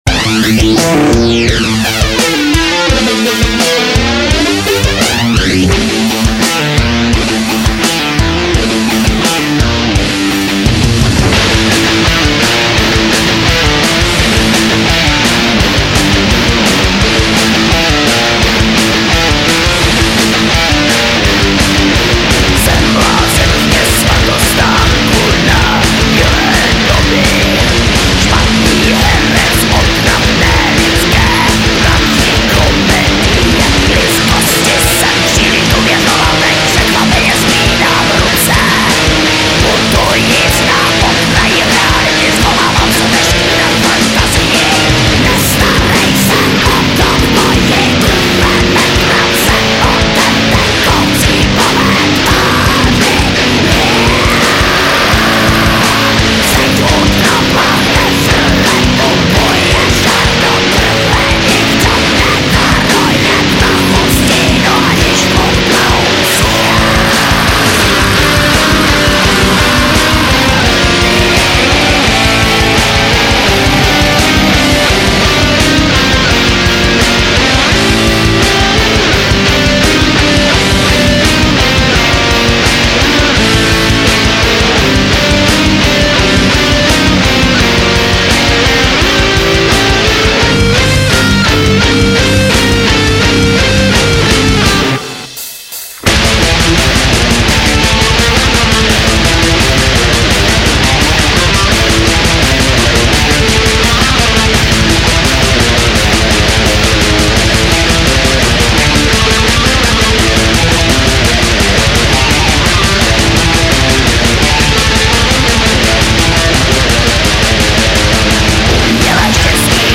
black/death metalové
moderní metalové hudby
ve studiu
kytara, zpěv, basa